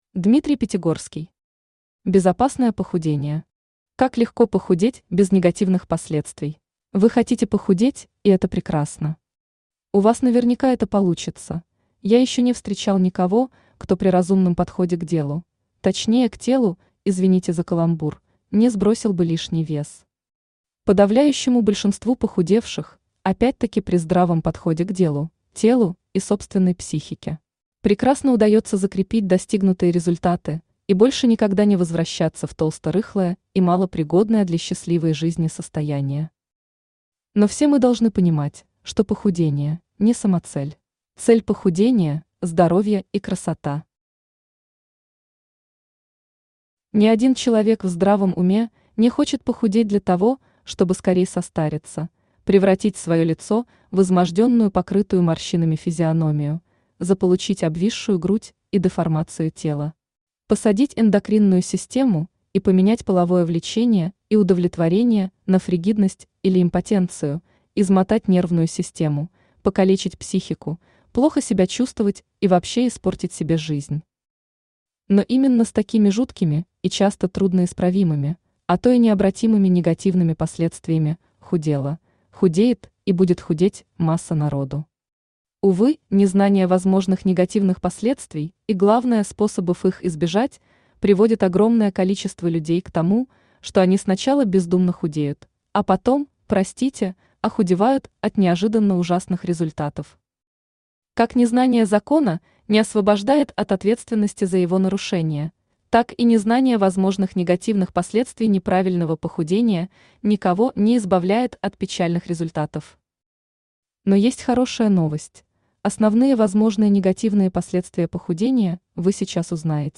Аудиокнига Безопасное похудение. Как легко похудеть без негативных последствий | Библиотека аудиокниг
Aудиокнига Безопасное похудение. Как легко похудеть без негативных последствий Автор Дмитрий Пятигорский Читает аудиокнигу Авточтец ЛитРес.